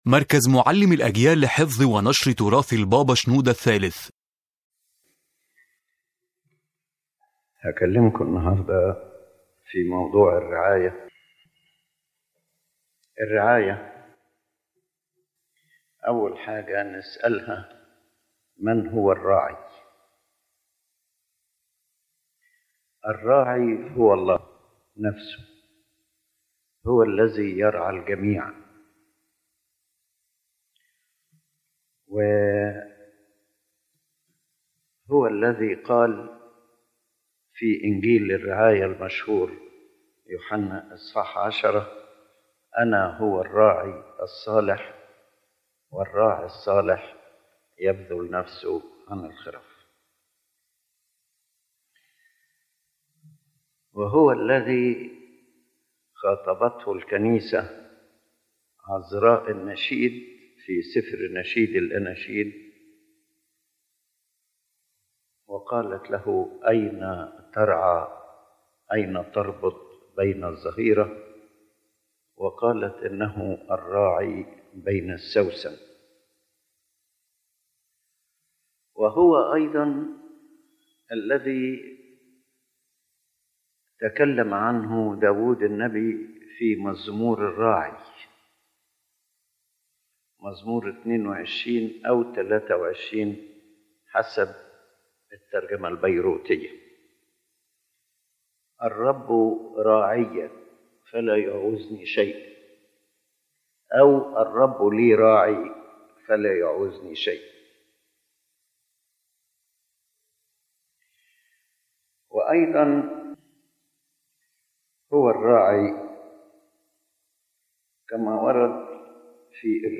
⬇ تحميل المحاضرة أولًا: الله هو الراعي الحقيقي تؤكد المحاضرة أن الراعي الحقيقي هو الله نفسه، فهو الذي يرعى الجميع بعناية ومحبة.